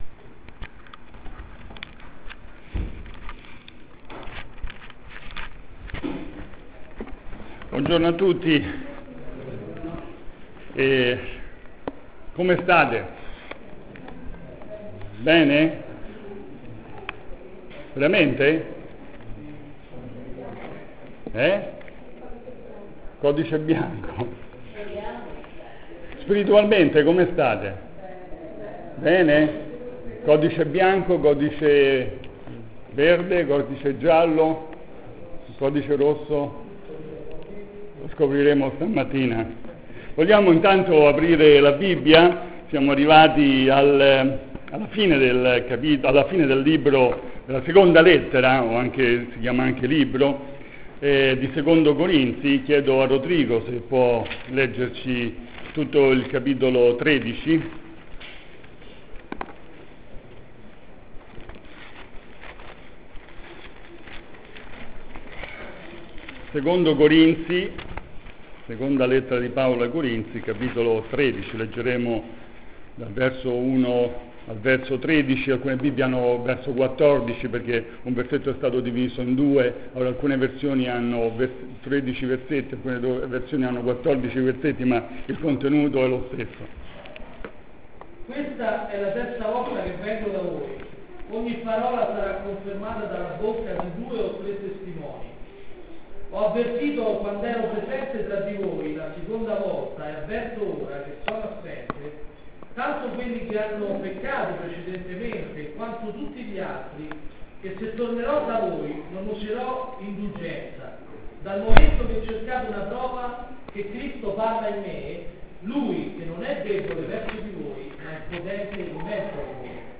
il messaggio sulla seconda lettera ai Corinzi